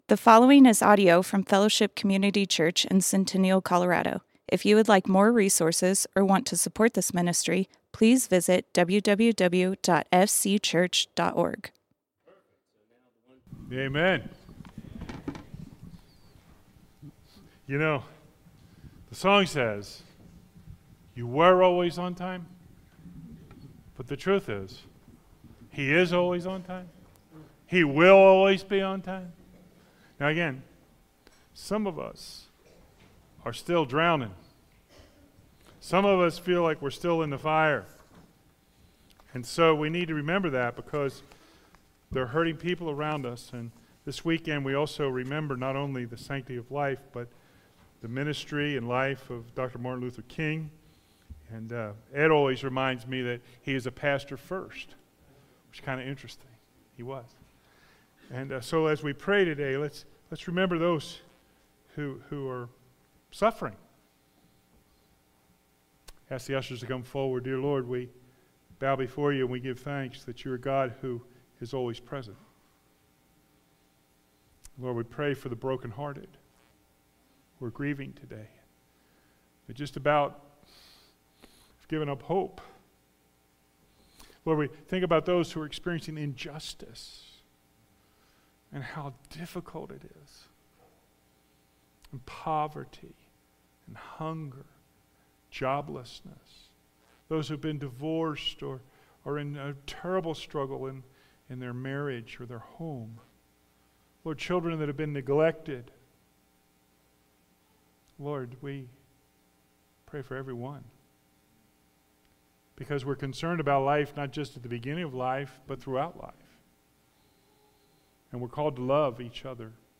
Fellowship Community Church - Sermons You Are Not Orphans Play Episode Pause Episode Mute/Unmute Episode Rewind 10 Seconds 1x Fast Forward 30 seconds 00:00 / 36:32 Subscribe Share RSS Feed Share Link Embed